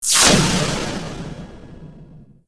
sounds_spacewar_weapons.dat
Laser03.wav